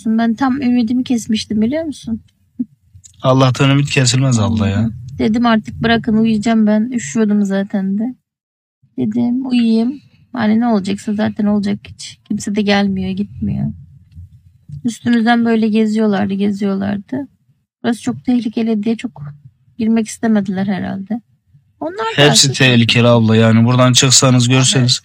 Deprem mağdurlarının sosyal medyada paylaştığı videoları tarayıp ses dosyalarına dönüştürerek internet sitesine yükledik.